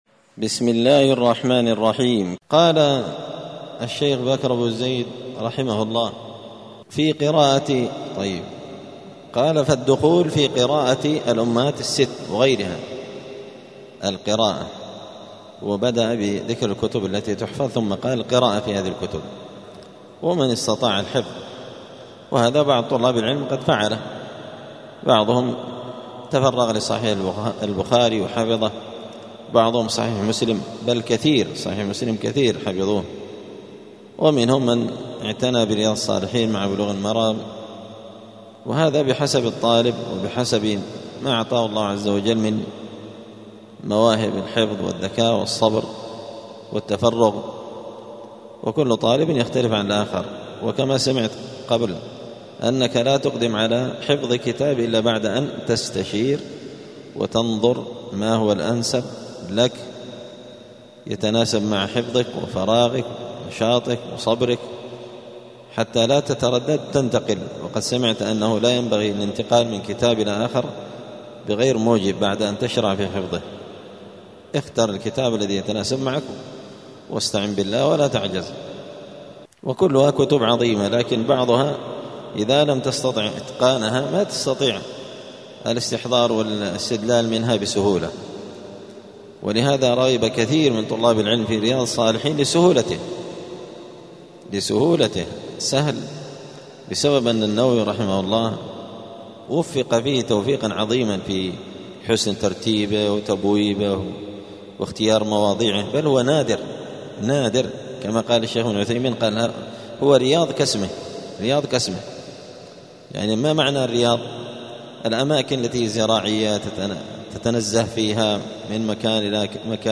*الدرس الثاني والعشرون (22) {فصل كيفية الطلب والتلقي اختيار الكتب للمبتدئ وغيره}*
دار الحديث السلفية بمسجد الفرقان قشن المهرة اليمن